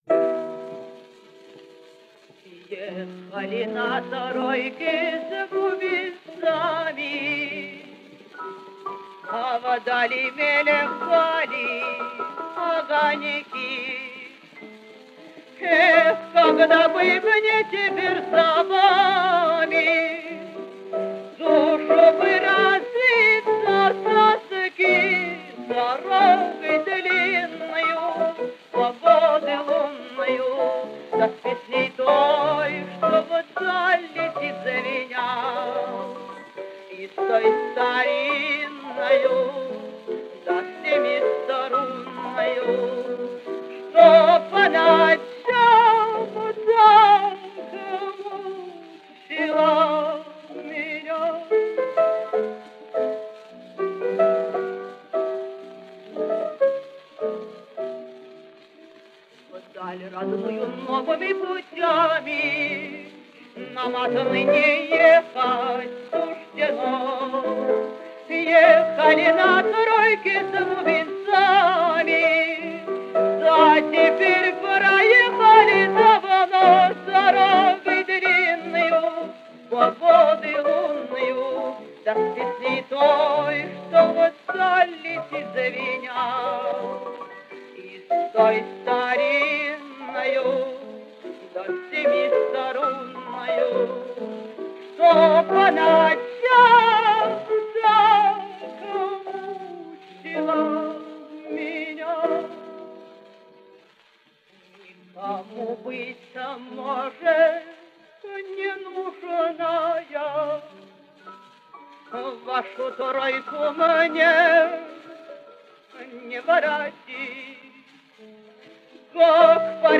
Жанр: Романсы